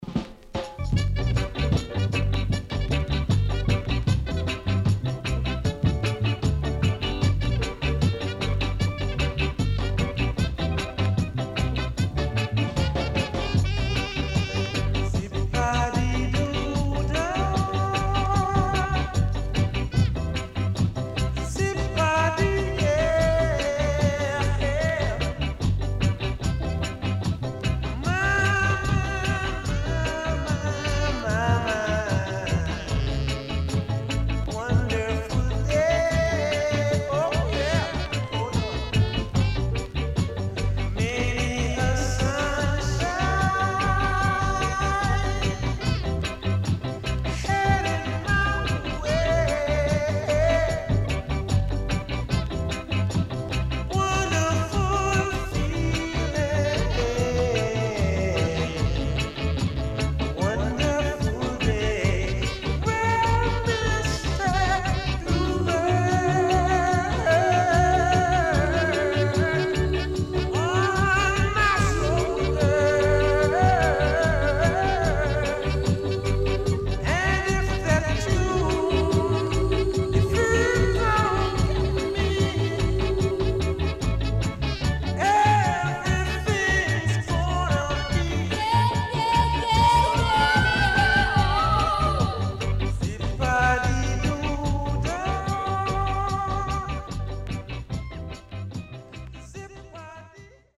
SIDE B:所々チリノイズがあり、少しプチノイズ入ります。